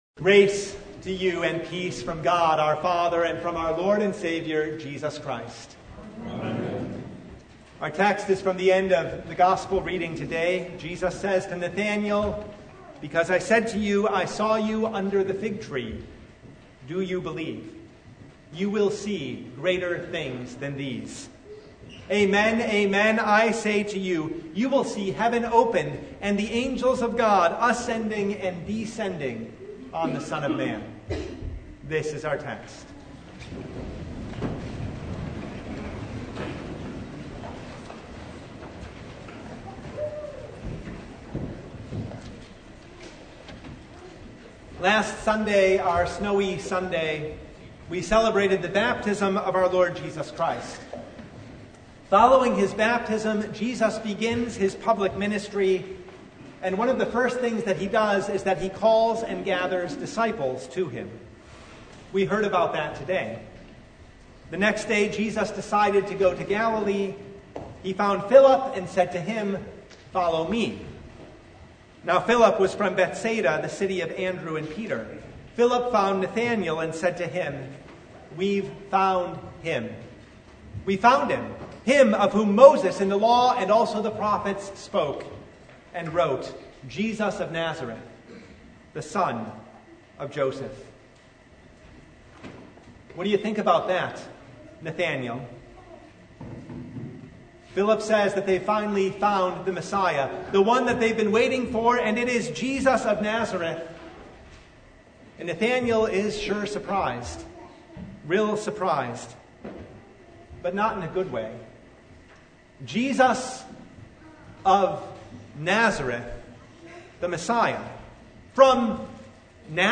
John 1:43-51 Service Type: Sunday Philip invited skeptical Nathanael to “come and see.”